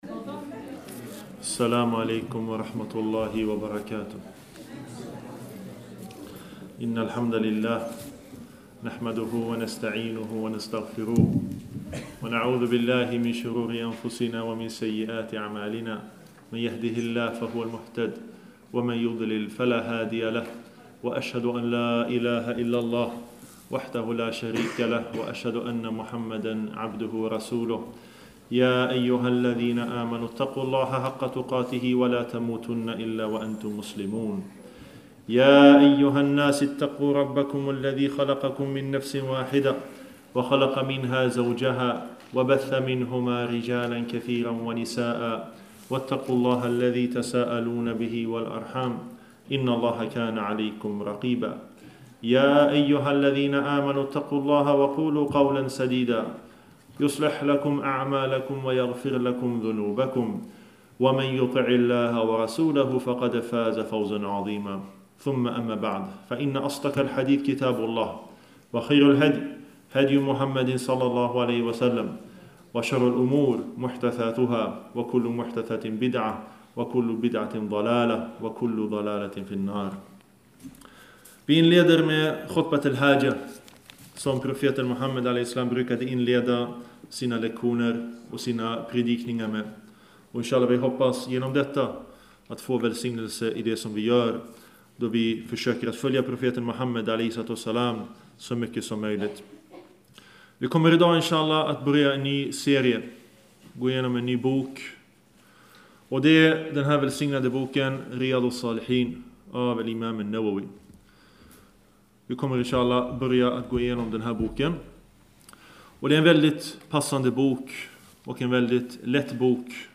En föreläsning av